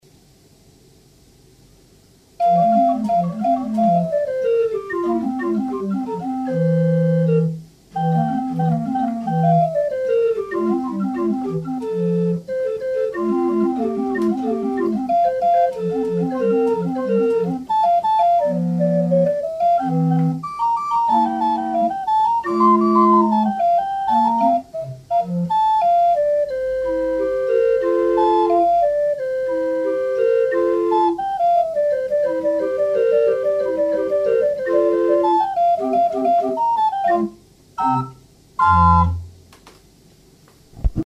Quite soon it was working and being played very well by visiting experts organists from Surrey.